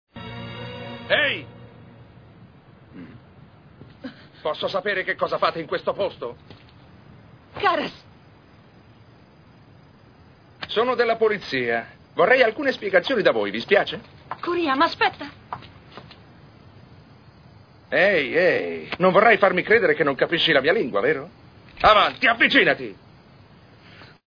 dal cartone animato "Noein"